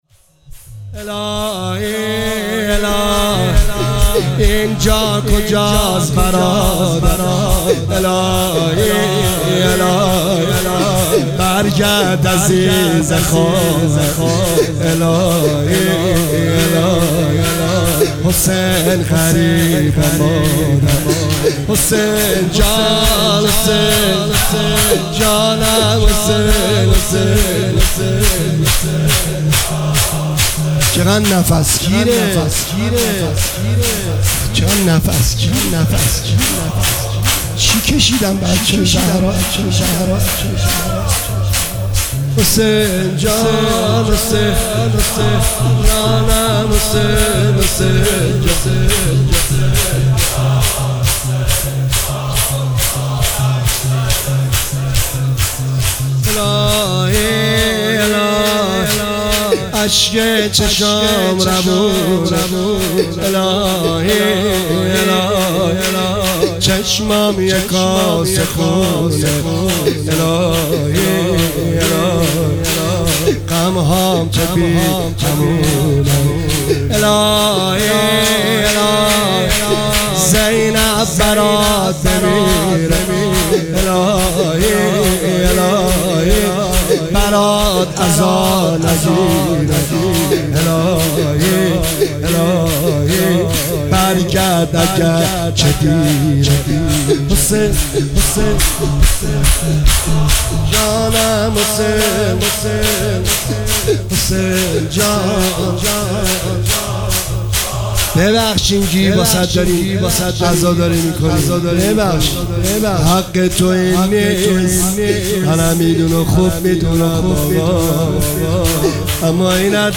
مداحی شور روز دوم محرم